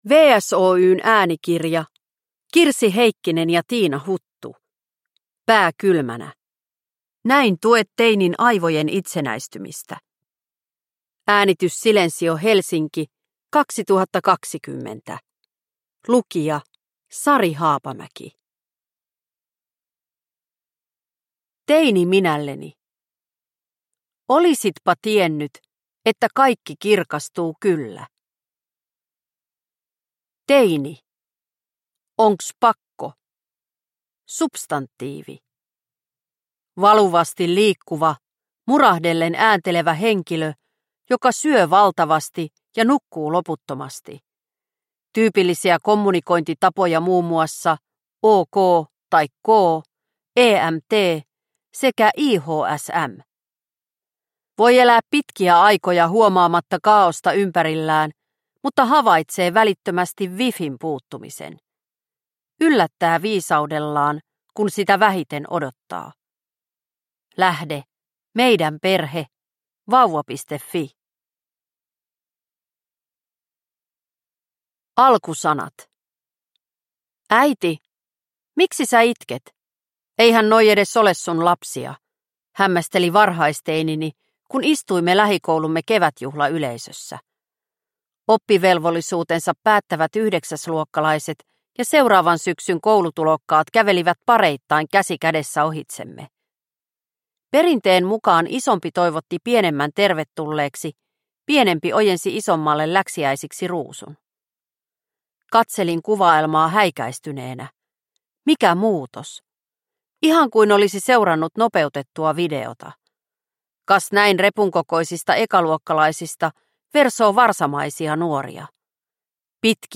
Pää kylmänä – Ljudbok – Laddas ner